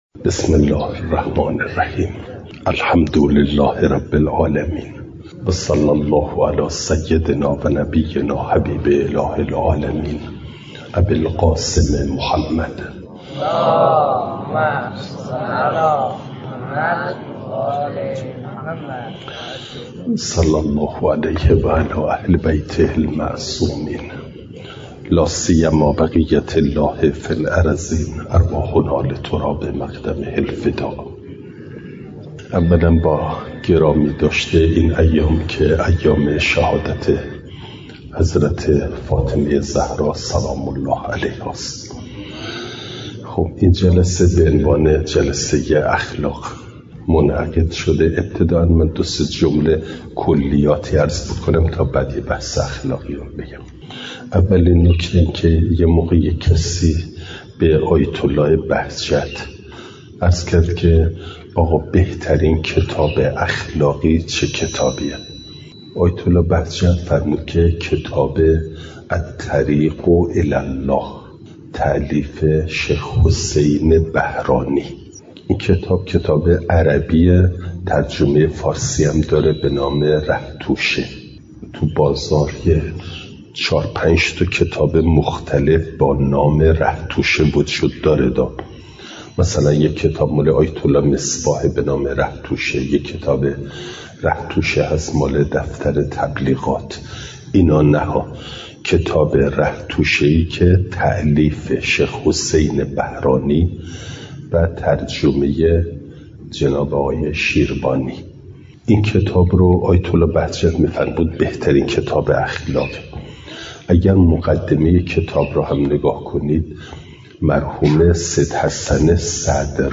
بیانات
پنجشنبه ۲۲ آبانماه ۱۴۰۴، باغملک، استان خوزستان